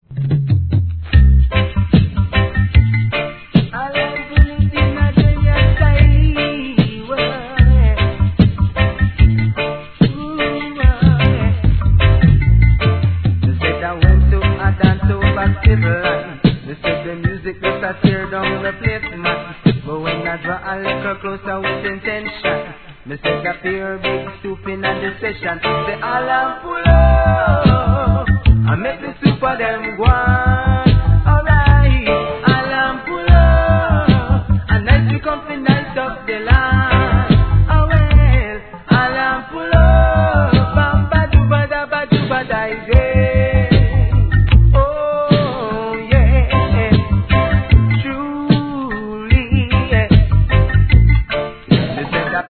REGGAE
EARLY '80s GOODヴォーカル!!